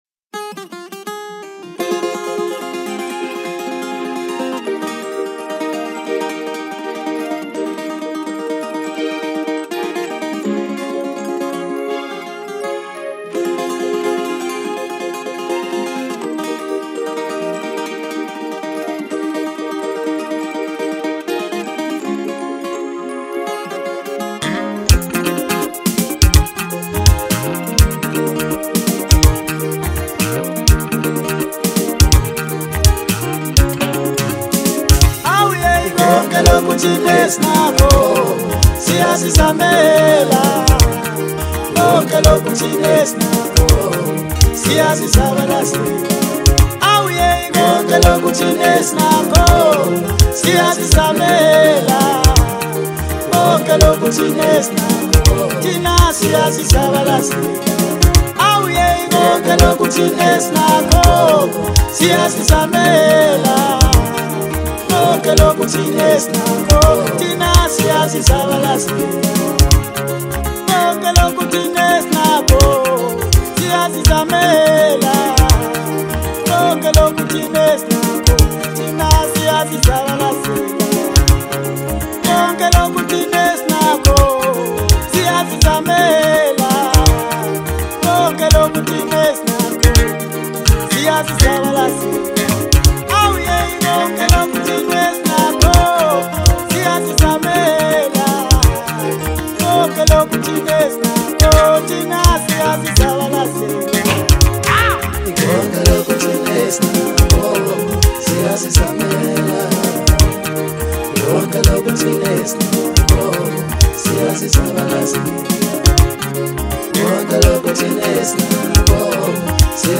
Gifted vocalist